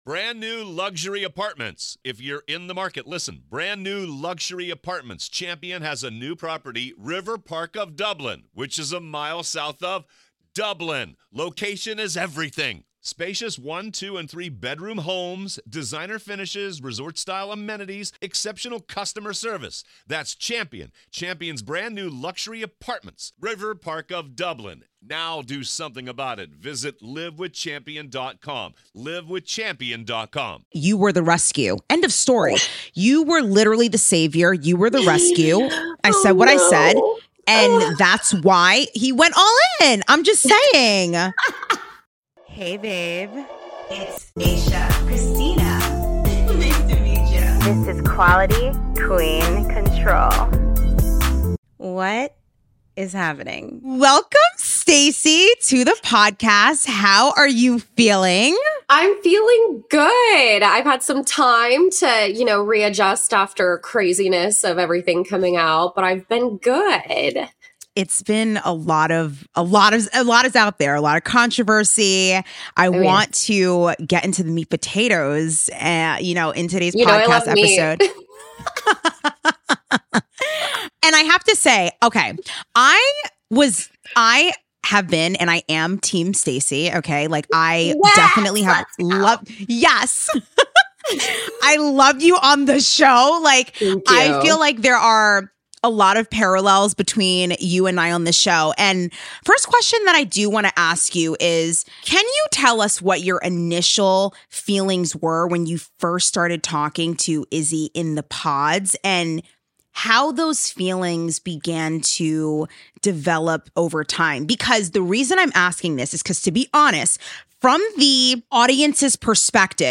Unpacking Relationships on Reality TV: A Conversation